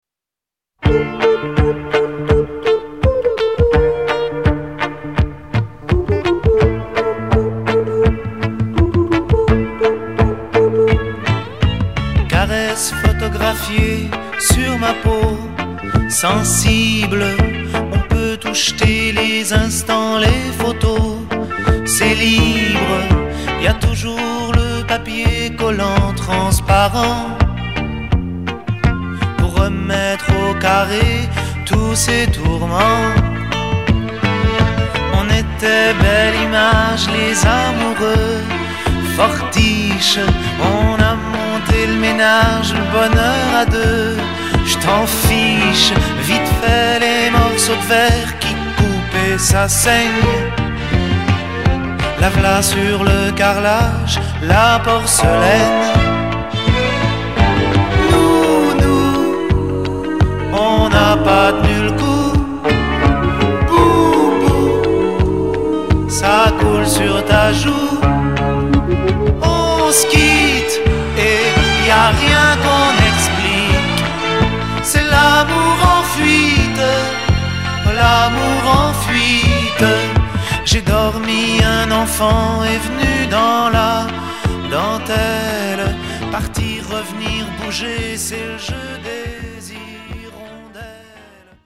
tonalités FA majeur et LA majeur